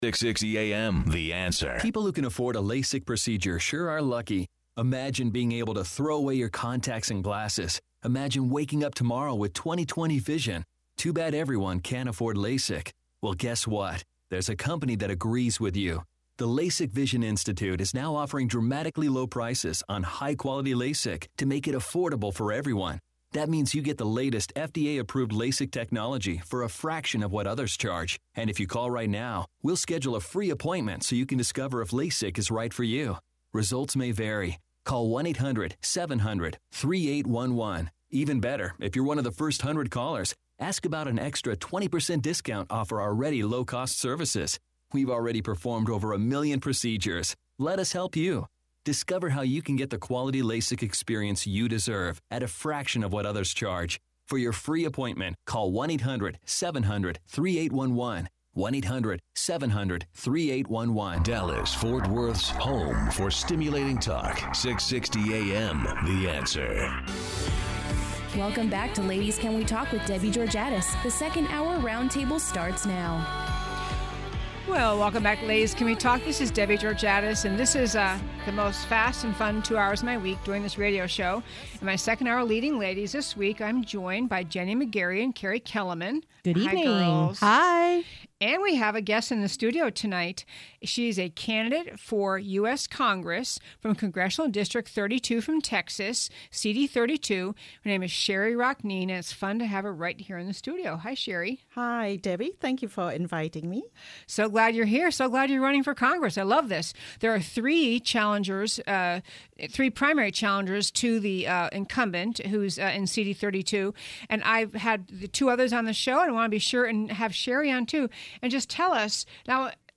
Radio Show Podcasts